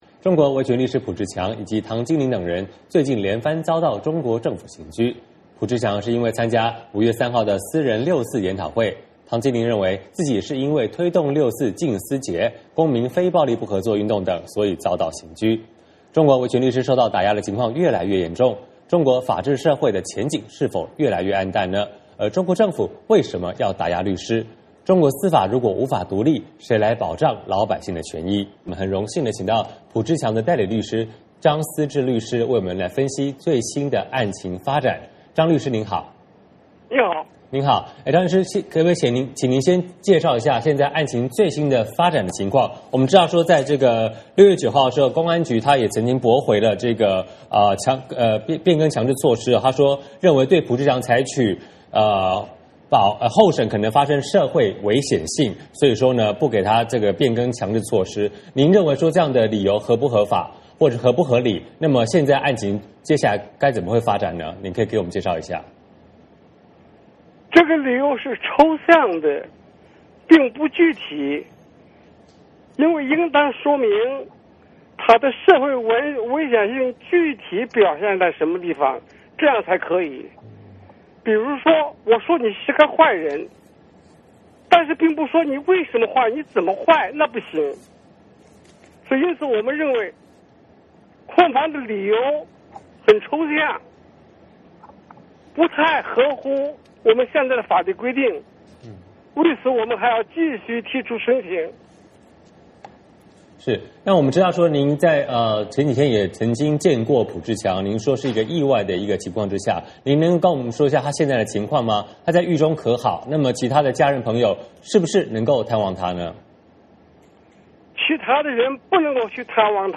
张思之律师接受美国之音采访，谈浦志强案件的最新进展。